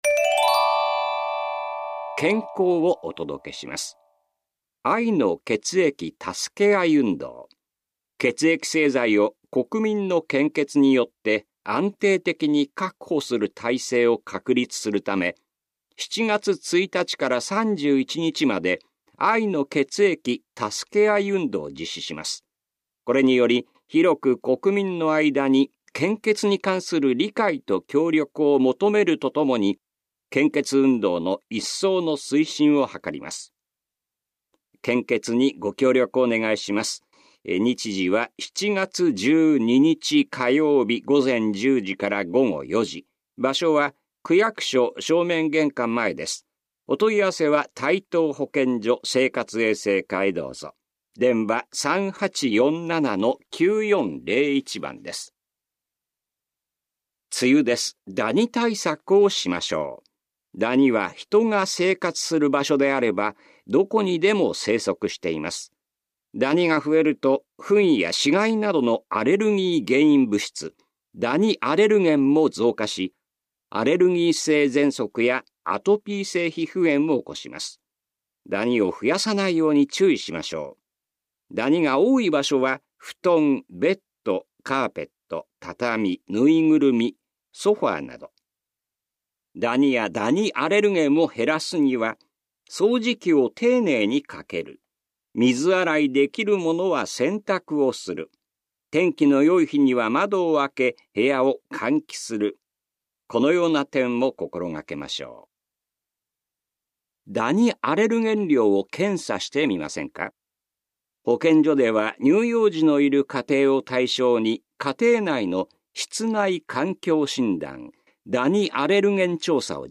広報「たいとう」令和4年6月20日号の音声読み上げデータです。